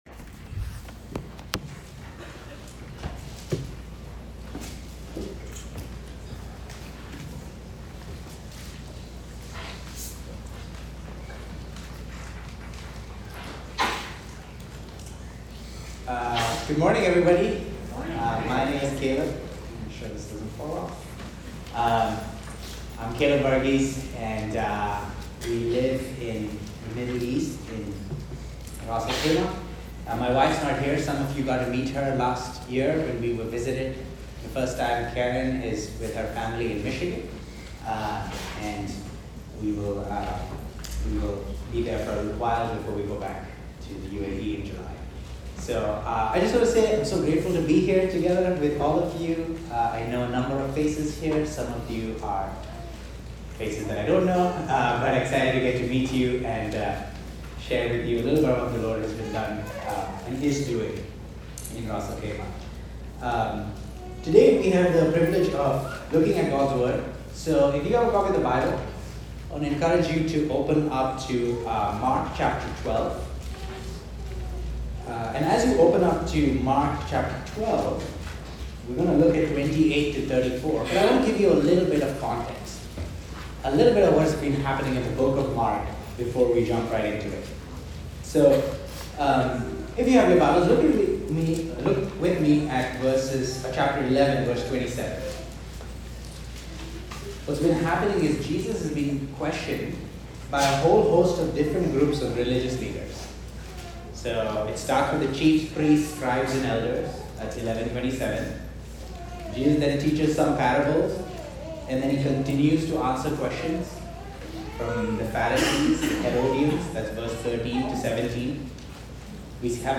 Bethany Baptist Church Listen to Sermons